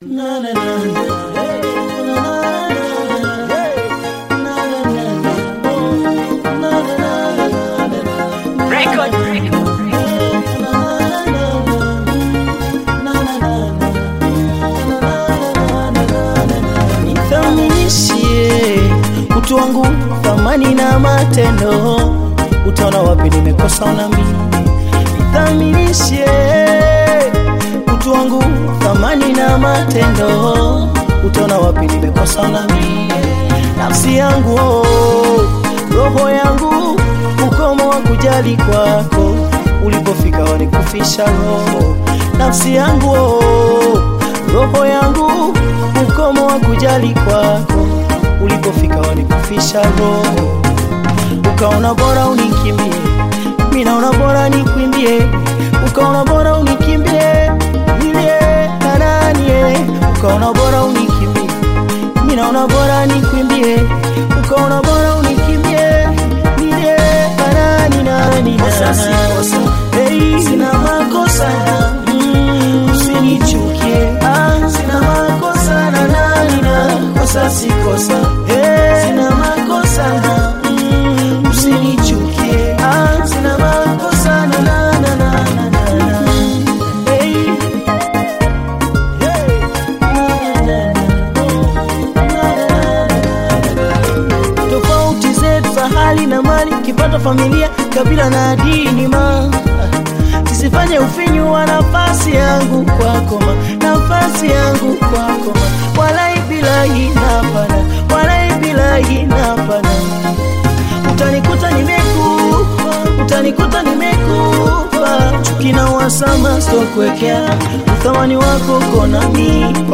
AudioBongo fleva
classic Bongo Flava track